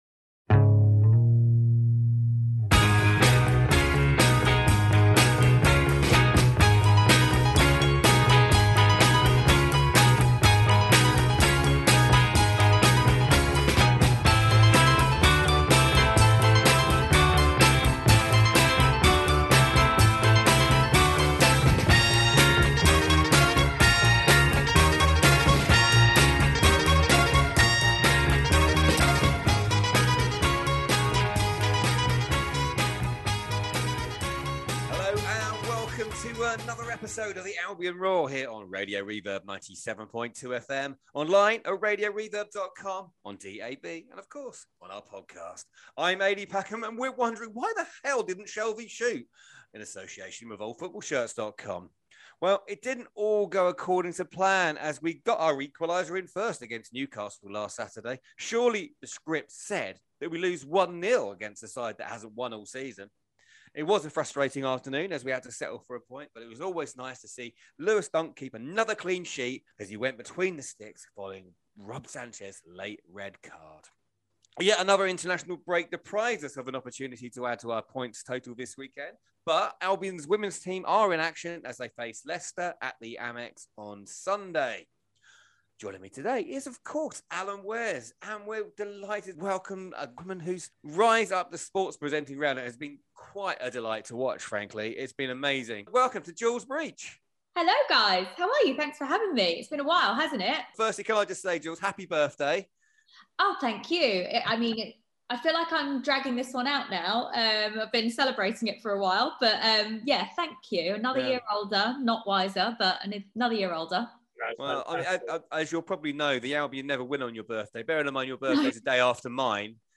Albion fan and BT Sport presenter Jules Breach for an hour long natter about her career so far, her love of the Albion, and her role in inspiring more women into sports journalism and broadcasting.